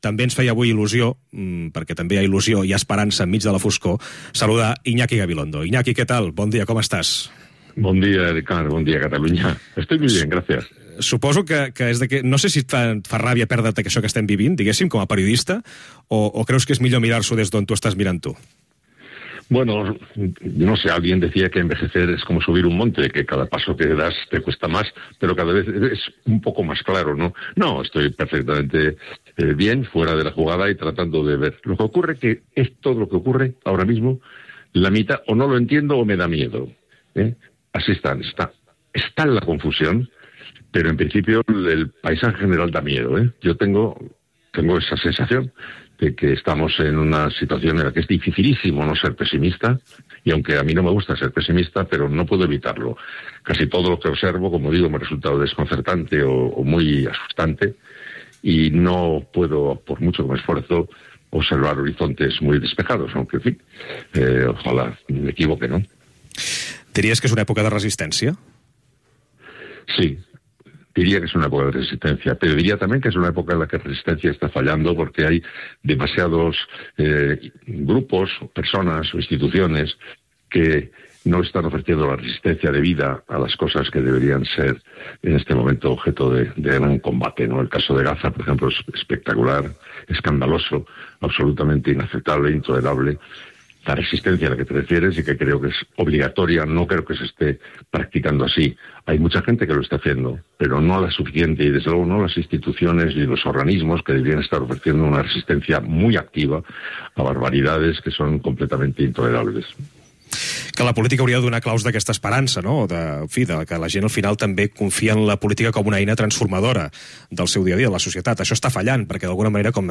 Entrevista al periodista Iñaki Gabilondo sobre l'actualitat de l'any.
Info-entreteniment